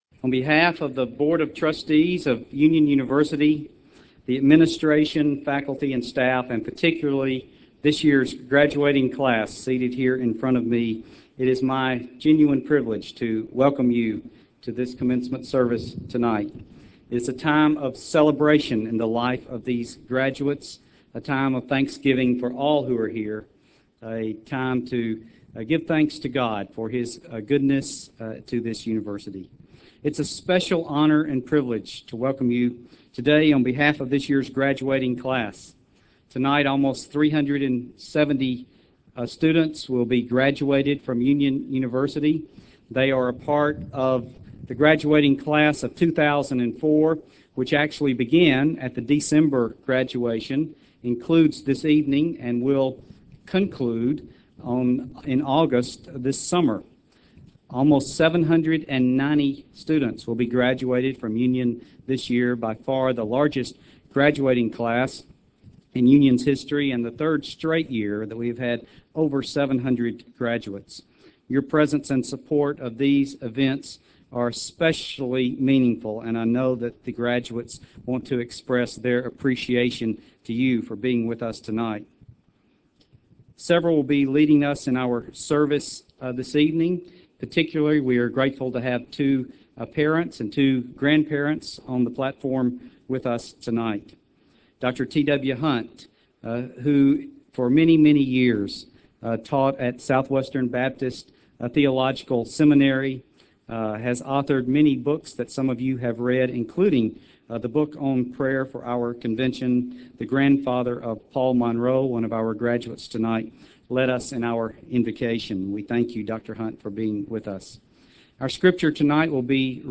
Spring Commencement: Opening
Scripture Reading